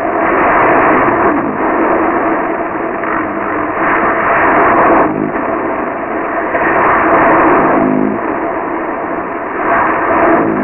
Mars Microphone: Test Sound Data (Sand Booming)
boomsand.wav